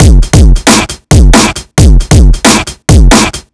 Overload Break 3 135.wav